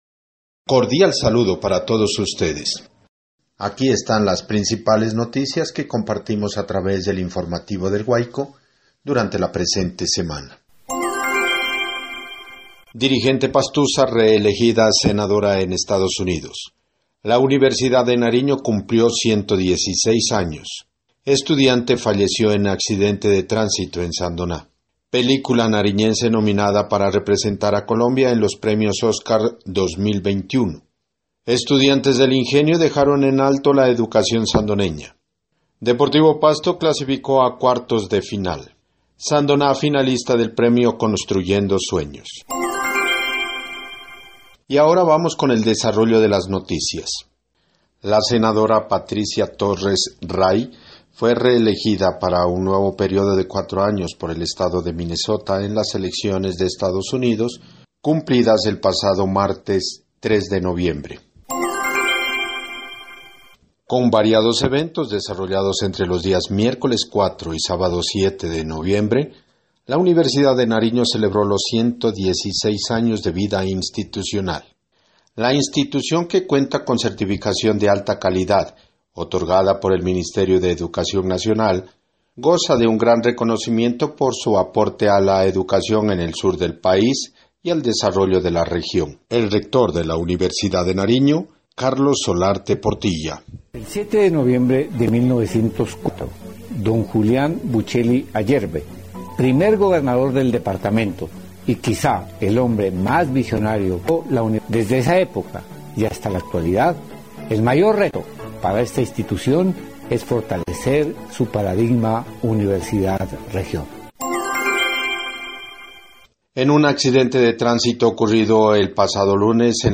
Resumen semanal de noticias (audio 9)